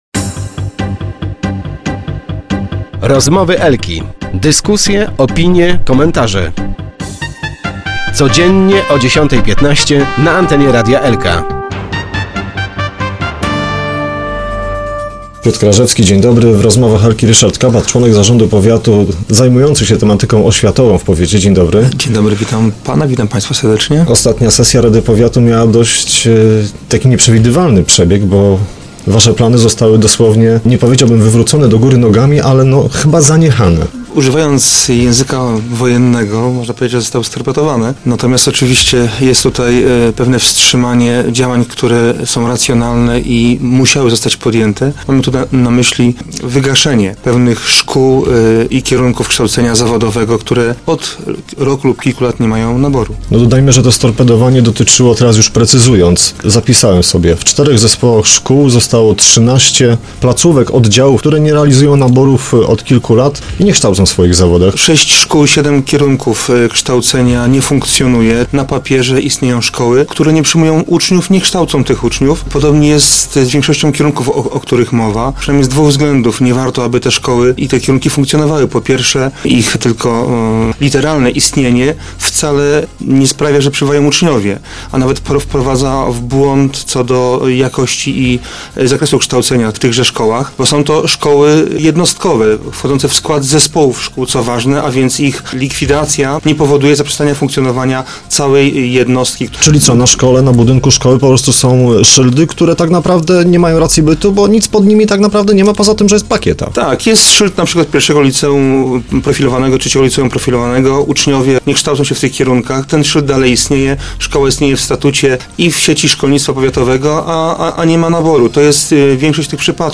W Rozmowach Elki rozmawialiśmy z Ryszardem Kabatem/na zdj/, członkiem zarządu powiatu ds. oświatowych.